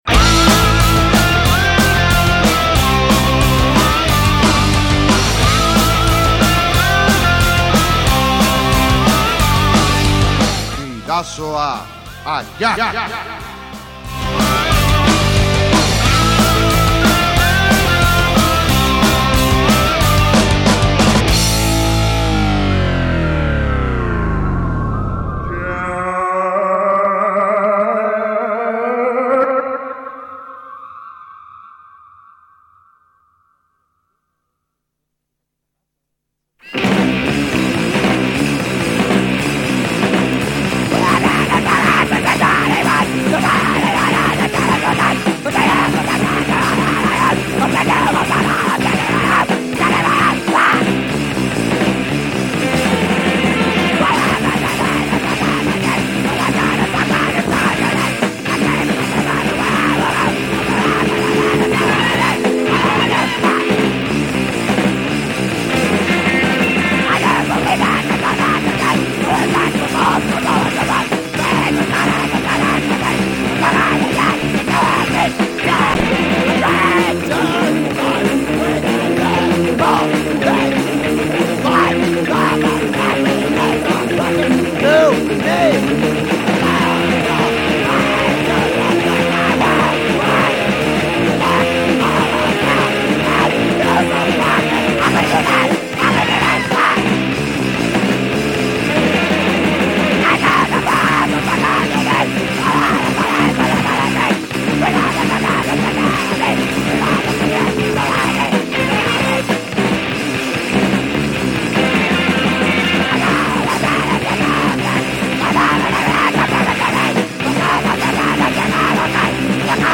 saio epiko, zaratatsu eta azkarra izango da